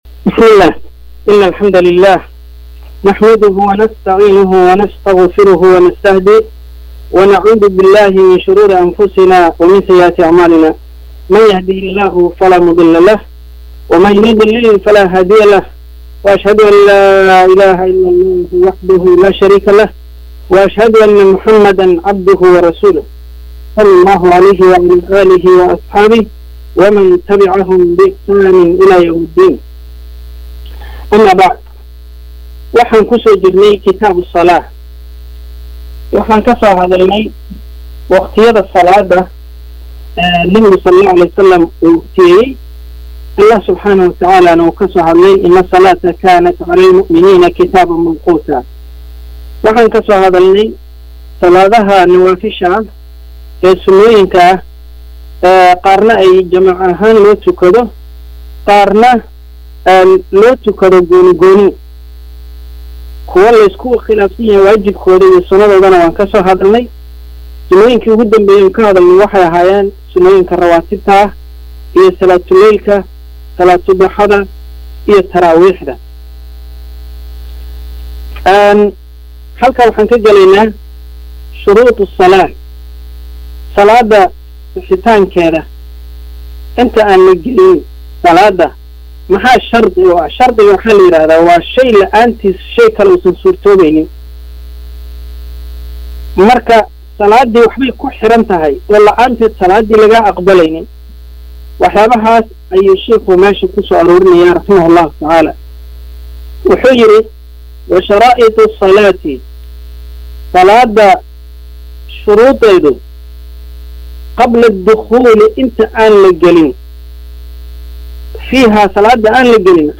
DHEGAYSO…CASHARKA 18AAD EE KITAABKA ABUU-SHUJAAC